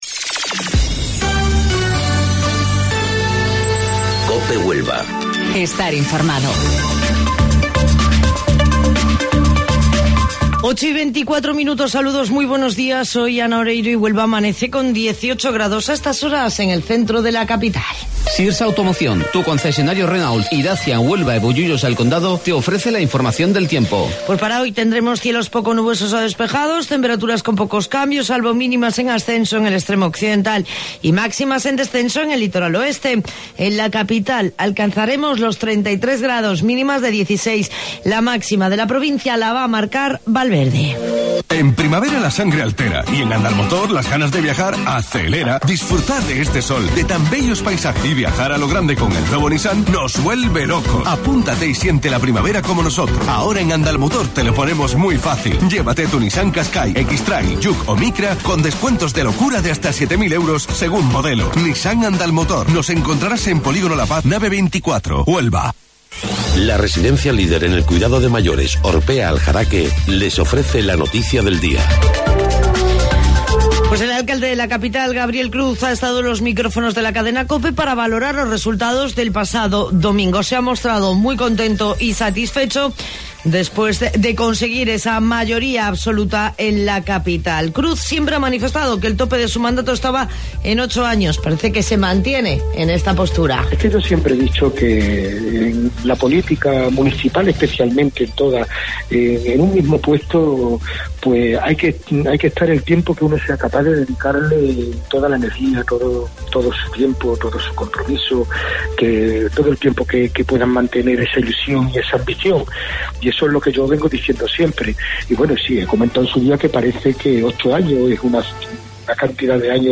AUDIO: Informativo Local 08:25 del 29 de Mayo